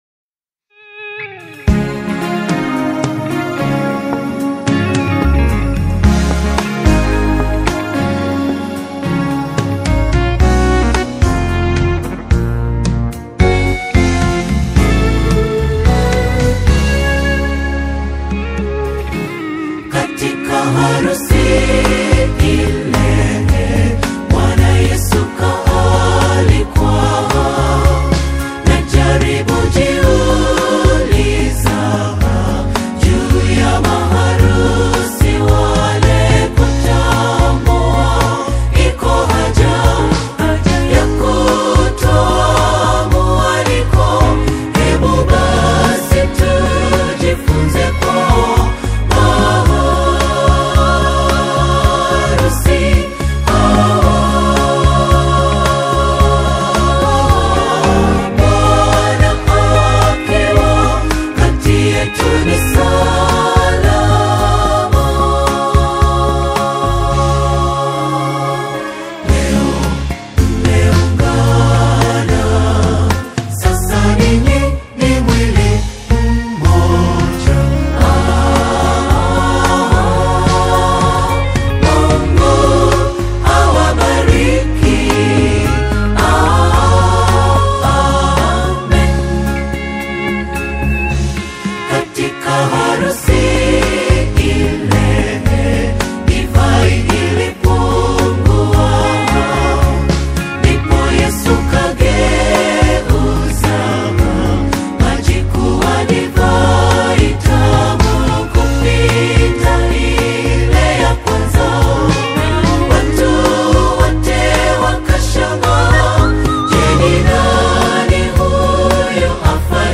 a celebratory and spirit-filled single
upbeat, rhythmic arrangements
a dedicated choral group